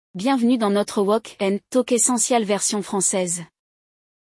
No episódio de hoje, vamos explorar um diálogo animado sobre esse momento especial, aprendendo novas palavras e expressões úteis em francês.
Neste episódio, acompanhamos um grupo de amigas reagindo com entusiasmo ao noivado de uma delas.